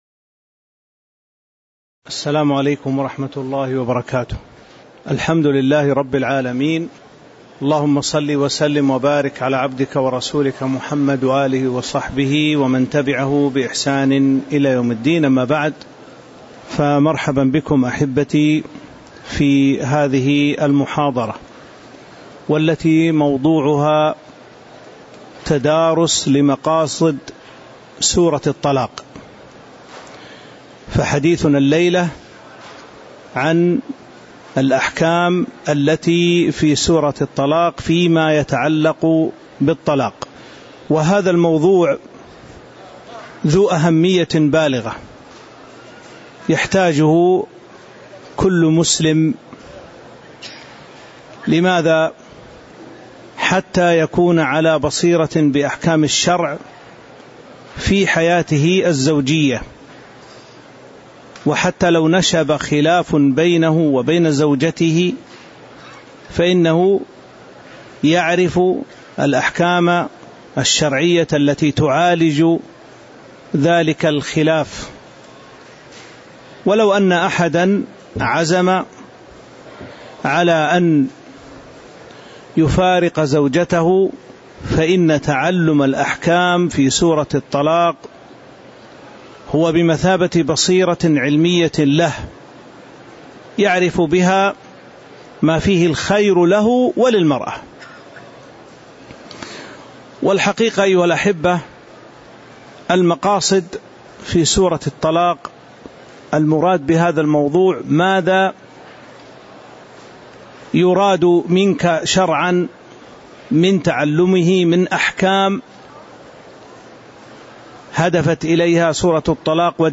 تاريخ النشر ٢٤ ربيع الأول ١٤٤٥ هـ المكان: المسجد النبوي الشيخ